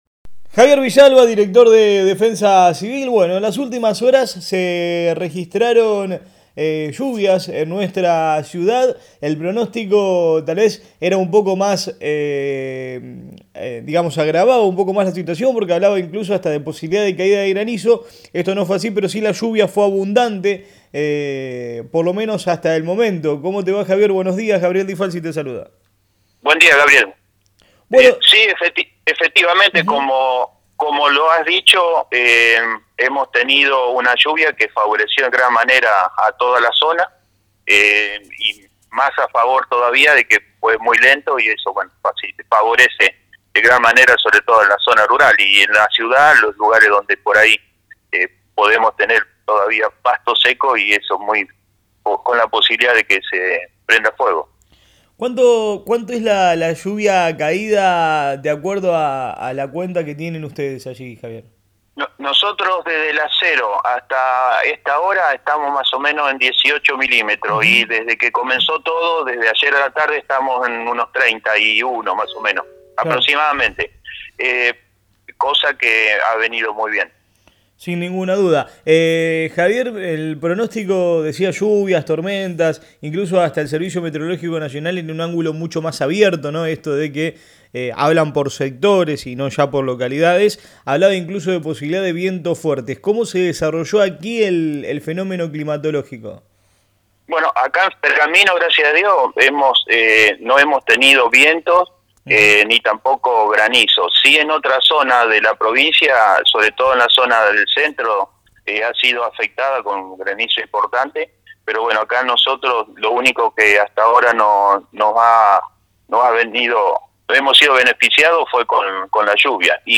Sobre estos fenómenos y la continuidad del tiempo para la región dialogamos con el director de Defensa Civil Pergamino, Javier Villalba: